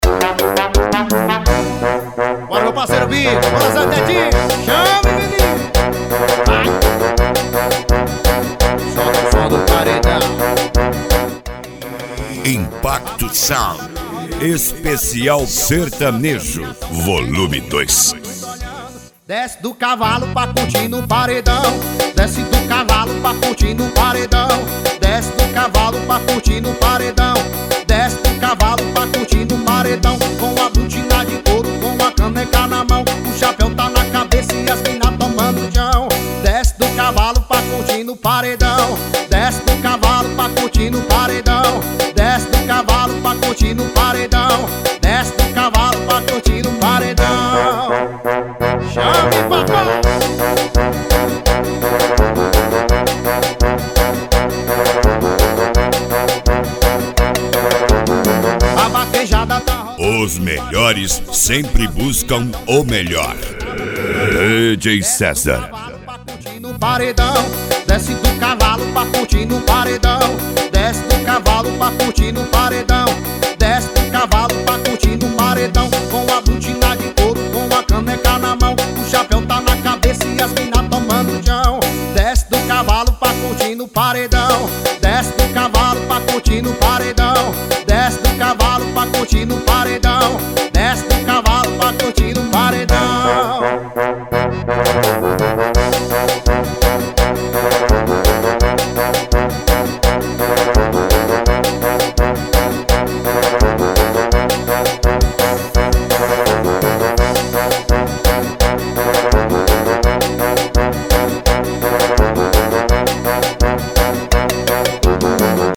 Modao
SERTANEJO
Sertanejo Raiz
Sertanejo Universitario